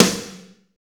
Index of /90_sSampleCDs/Northstar - Drumscapes Roland/DRM_Slow Shuffle/SNR_S_S Snares x